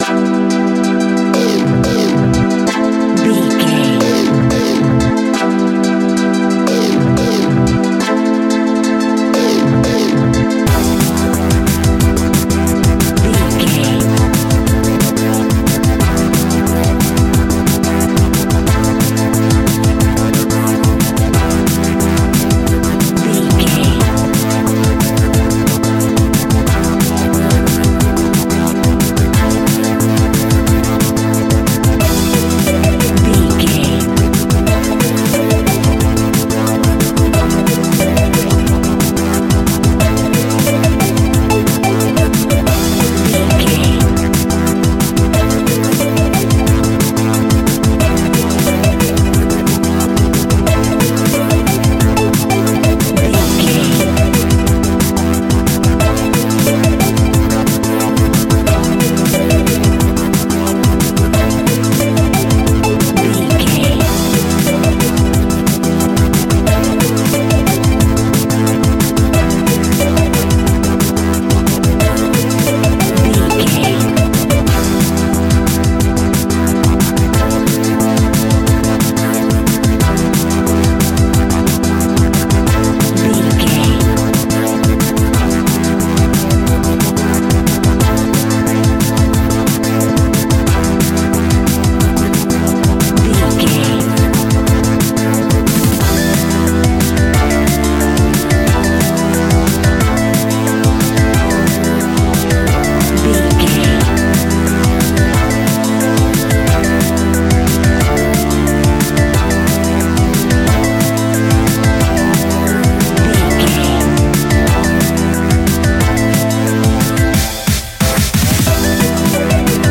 Aeolian/Minor
Fast
futuristic
hypnotic
industrial
dreamy
frantic
aggressive
dark
drum machine
synthesiser
break beat
electronic
sub bass
synth leads